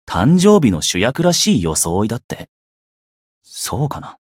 觉醒语音 誕生日の主役らしい装いだって？…